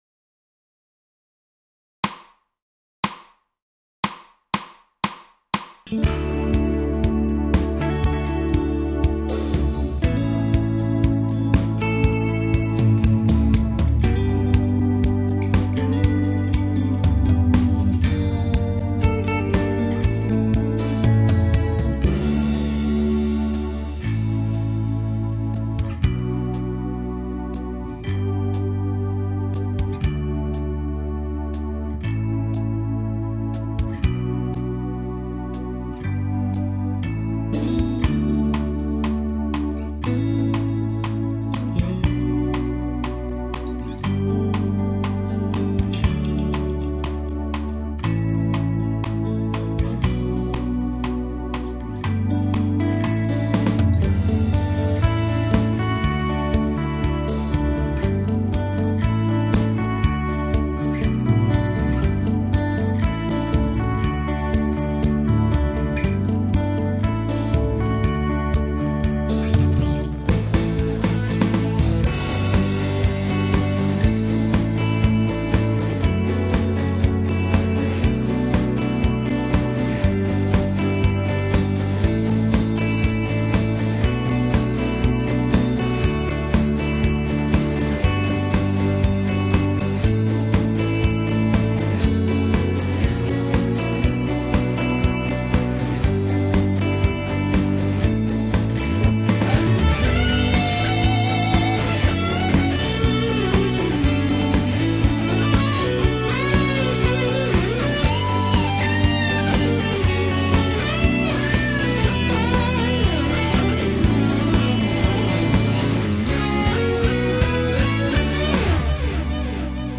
シンセメロ無し、ボーカル録音用ファイル（ミラー）